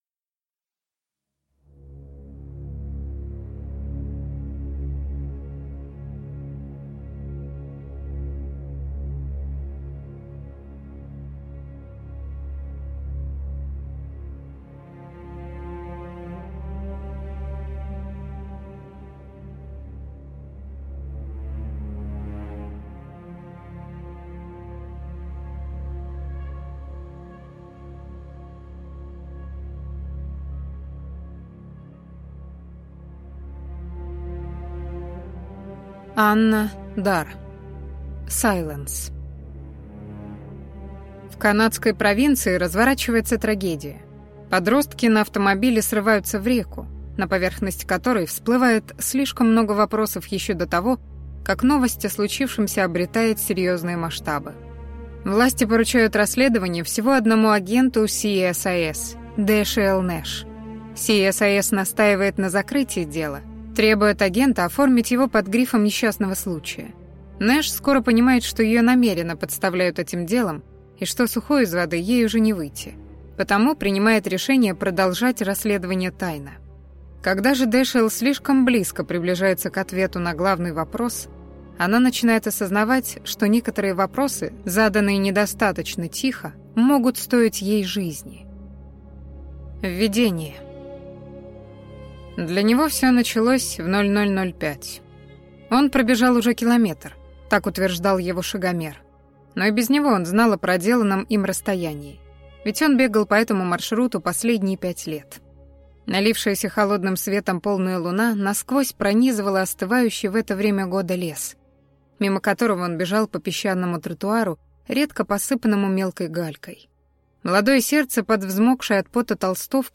Aудиокнига Silence